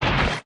tesla-turret-activate.ogg